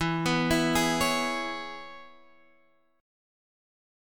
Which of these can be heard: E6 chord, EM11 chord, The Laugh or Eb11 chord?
E6 chord